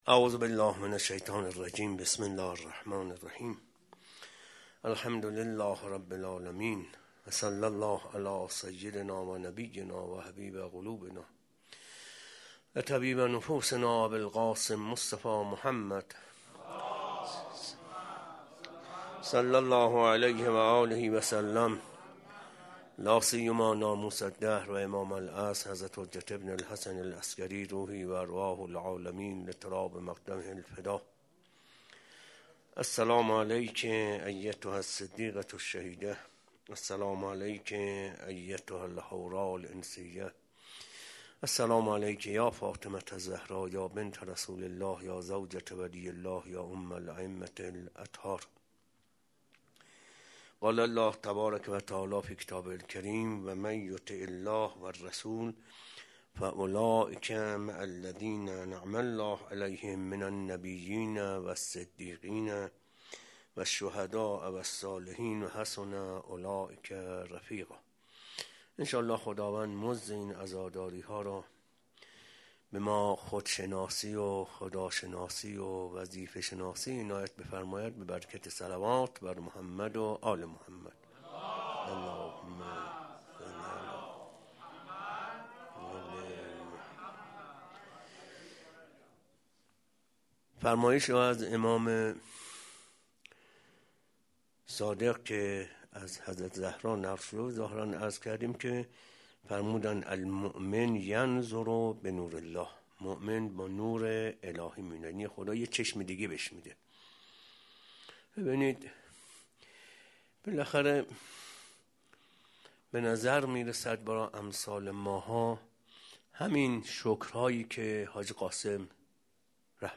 خیمه گاه - روضةالشهداء - سخنرانی